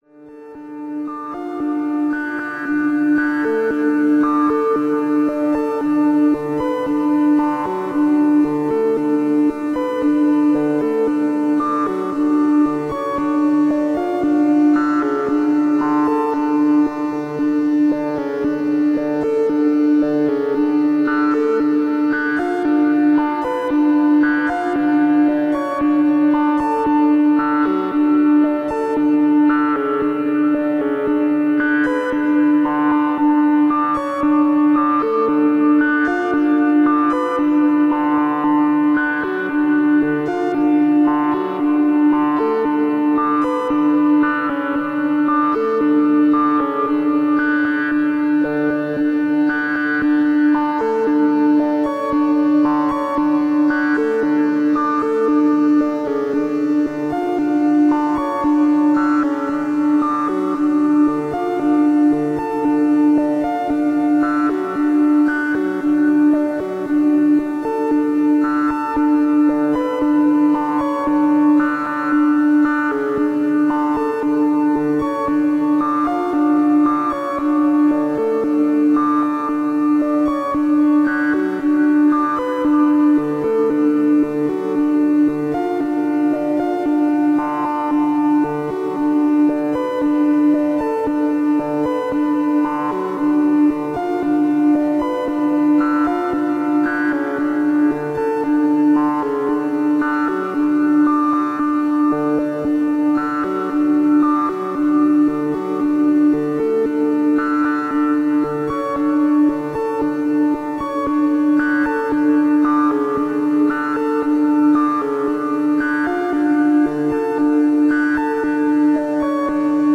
Kopfhörer oder Stereo-Lautsprecher empfohlen.
Behringer Neutron
FX von Strymon Bluesky